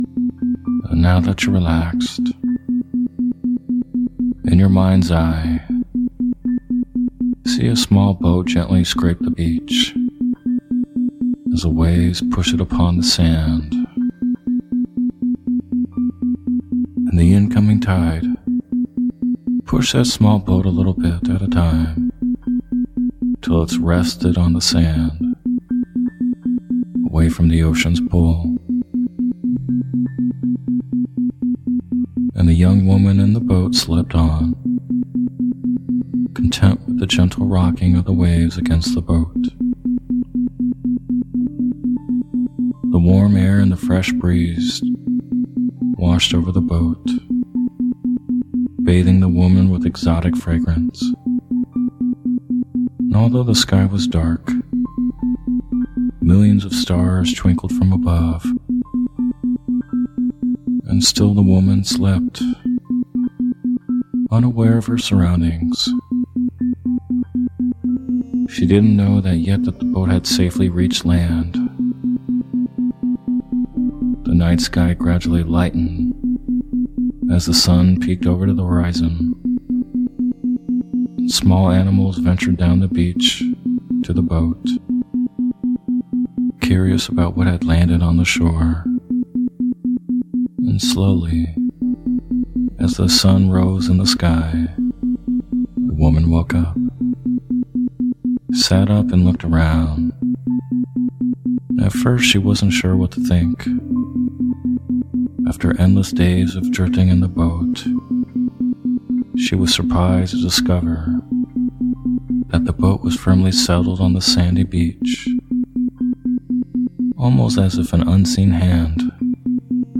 Story Based Meditation “The Tropical Forest” With Isochronic Tones
Tropical-Forest-Removing-Barriers-ISO-Sample.mp3